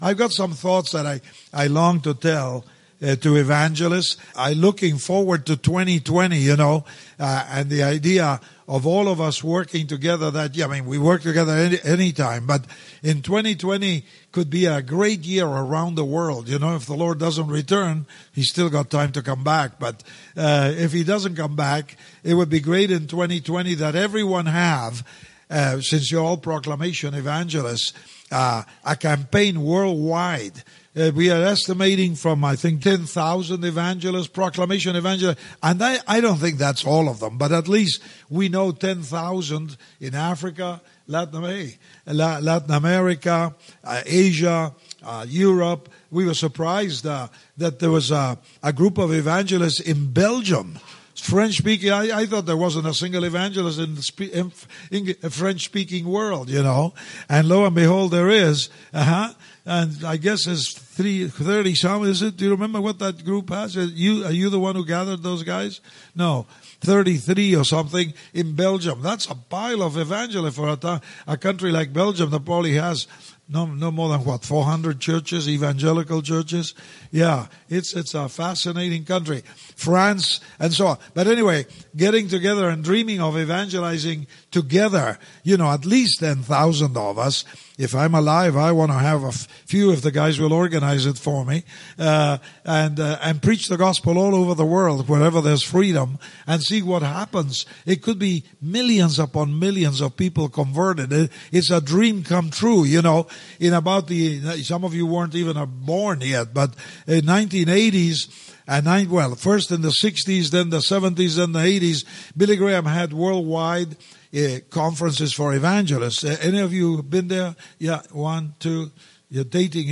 Luis Palau addresses NGA members at Extend the Impact in Grand Rapids.